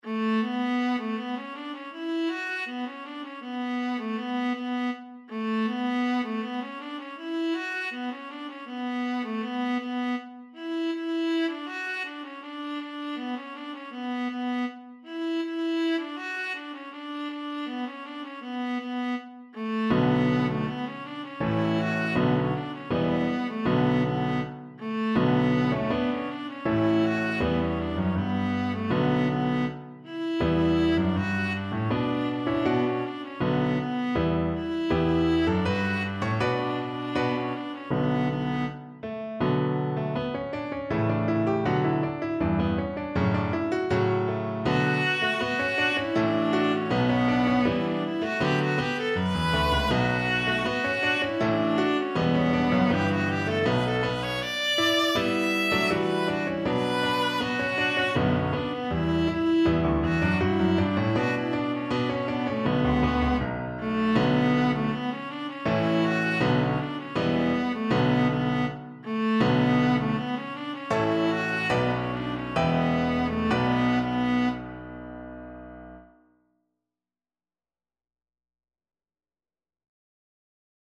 Traditional Trad. Ozi V'zimrat Ya (Jewish Shephardic) Viola version
Viola
D major (Sounding Pitch) (View more D major Music for Viola )
Decisive =c.80
3/4 (View more 3/4 Music)
A4-E6
Traditional (View more Traditional Viola Music)
world (View more world Viola Music)